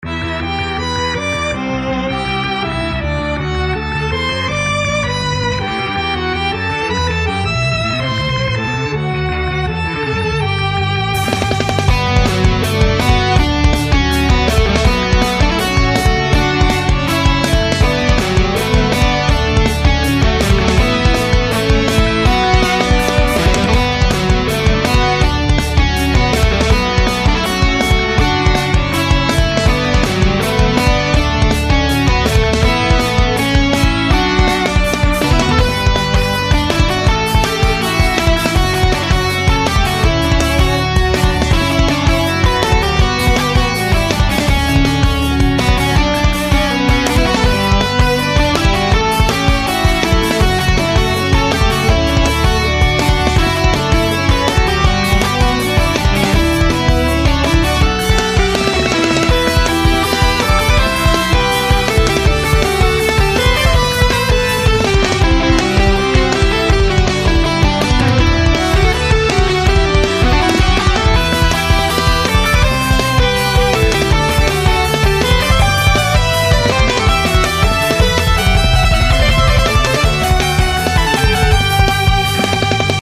それぞれ１ループの音源です♪
イントロあり